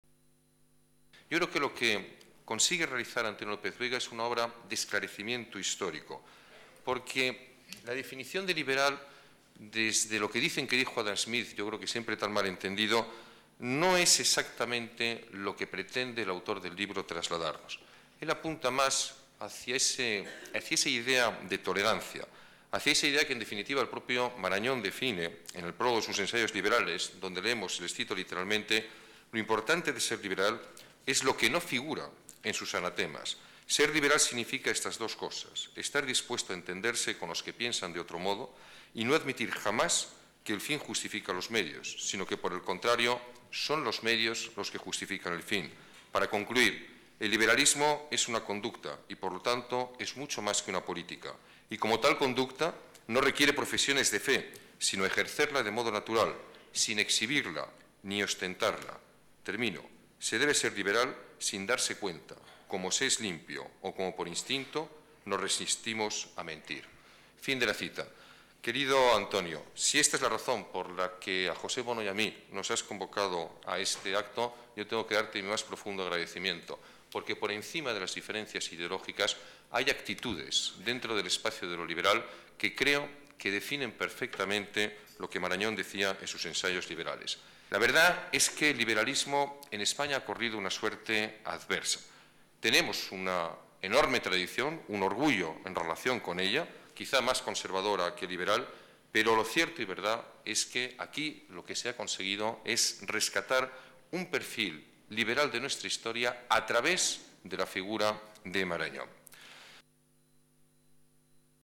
Nueva ventana:Declaraciones de Alberto Ruiz-Gallardón: devolver el protagonismo a Marañón